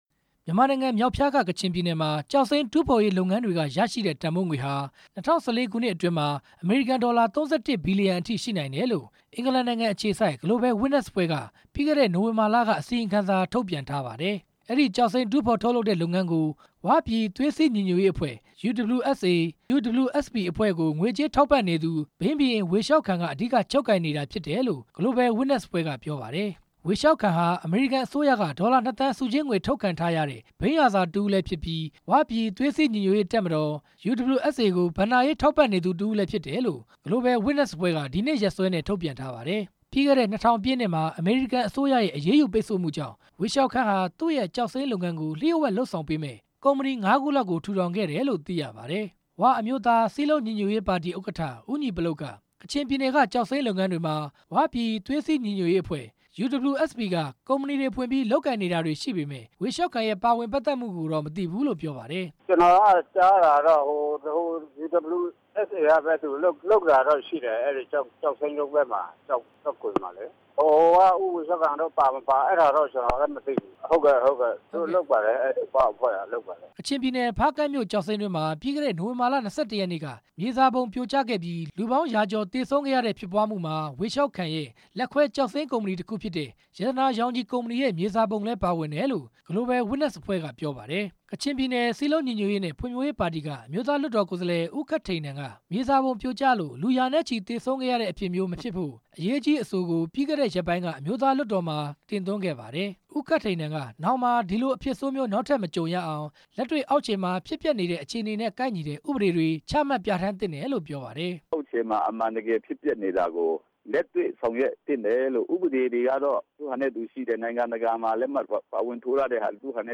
Global Witness အစီရင်ခံစာနဲ့ ပတ်သက်ပြီး နိုင်ငံရေးသမားတွေနဲ့ လေ့လာသုံးသပ်သူတွေရဲ့ အမြင်ကို